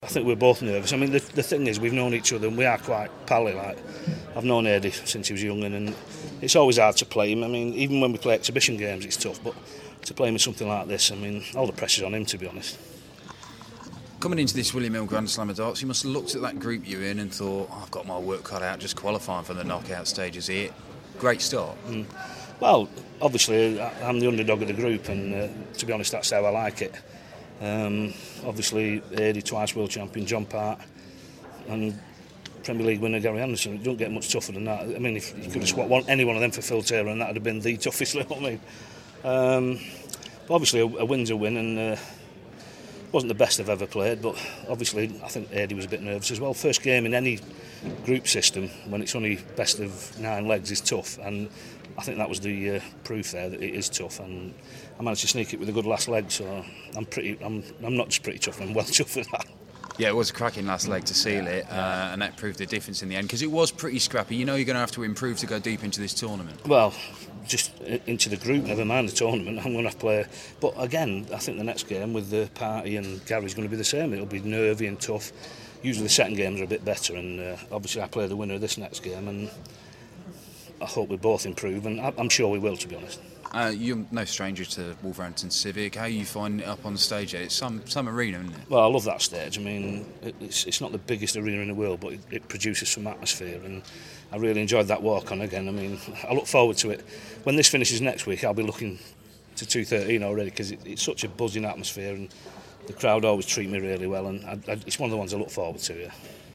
William Hill GSOD - O'Shea Interview